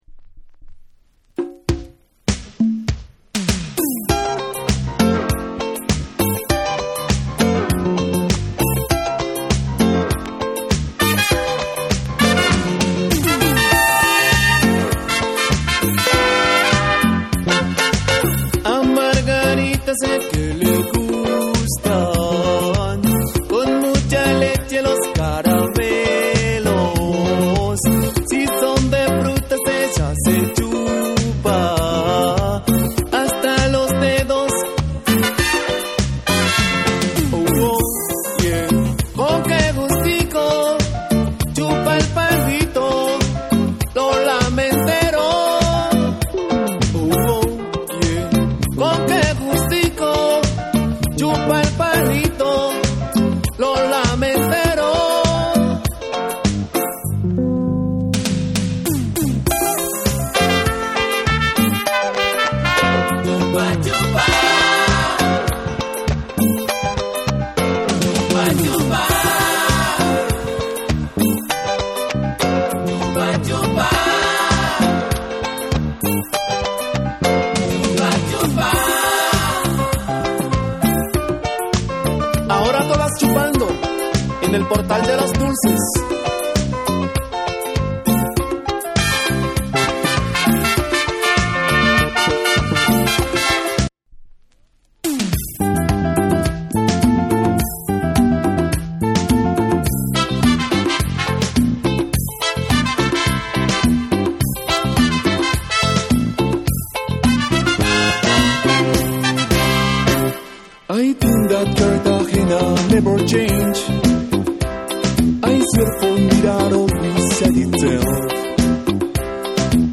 WORLD / LATIN